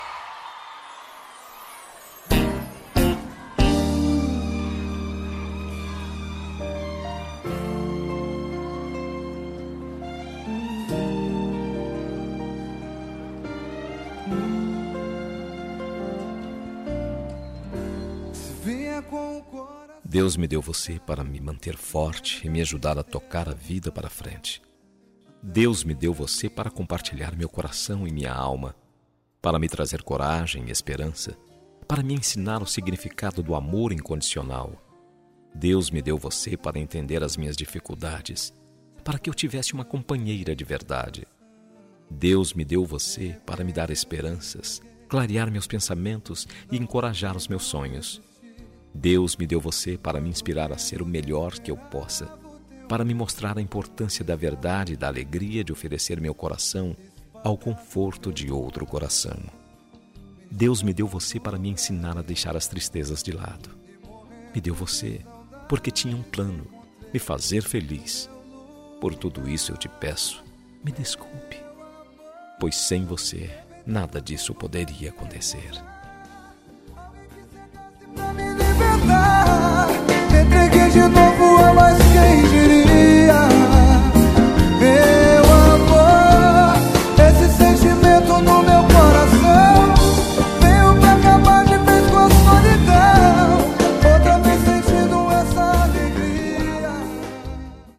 Telemensagem de Reconciliação – Voz Masculina – Cód: 7544